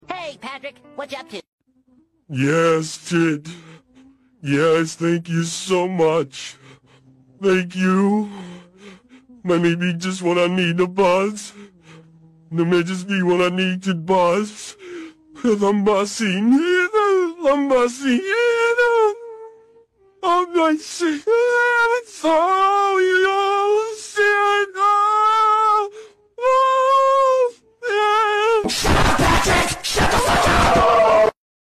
Follow for more deep fried FreakBob sound effects free download